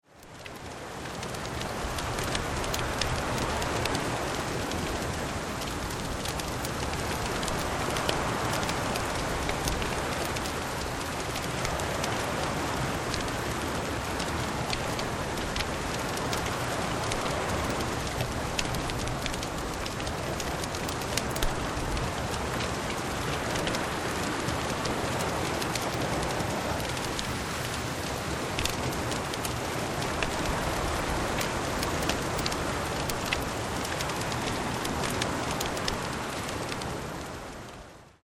6 Stunden Lagerfeuer am Strand
Bei Geräuschaufnahmen sind diese ebenfalls in 44.1 kHz Stereo aufgenommen, allerdings etwas leister auf -23 LUFS gemastert.
44.1 kHz / Stereo Sound
Lautstärke: -23 LUFS
Hoerprobe-Lagerfeuer-Strand.mp3